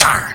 sentry_empty.wav